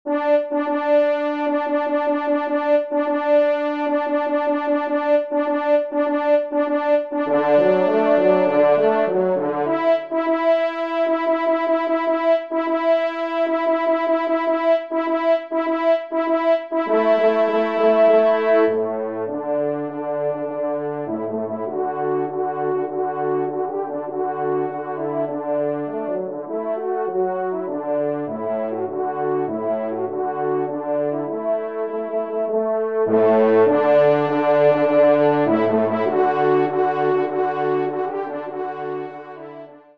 Genre :  Divertissement pour Trompes ou Cors en Ré
6e Trompe